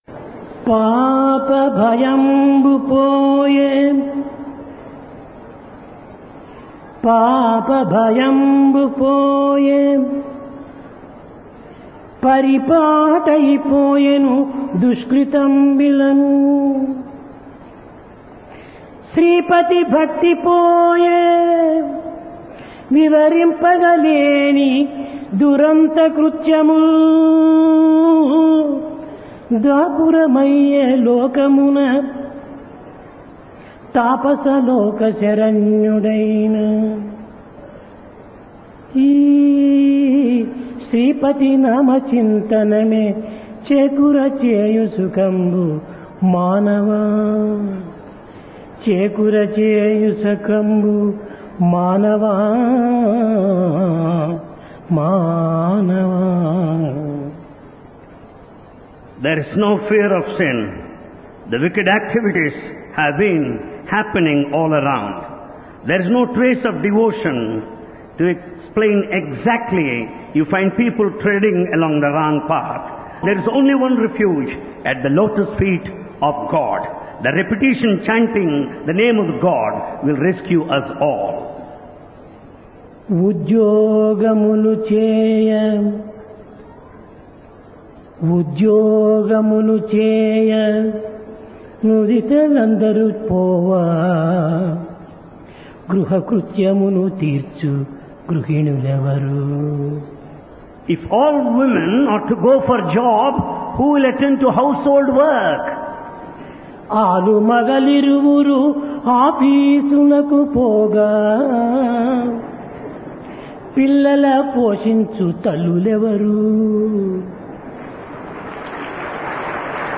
Divine Discourse | Sri Sathya Sai Speaks
Divine Discourse of Bhagawan Sri Sathya Sai Baba
Place Prasanthi Nilayam Occasion Dasara